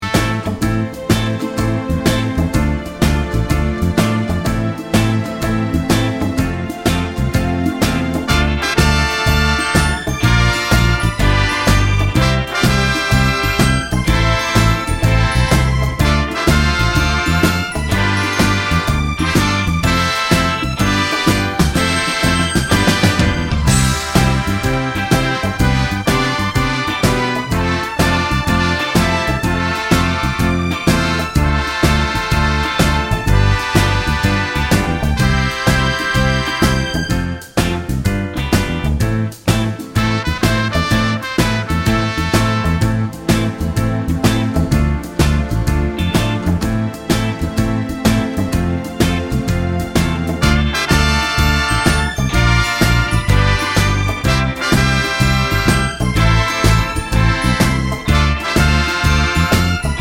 Professional Pop (1970s) Backing Tracks.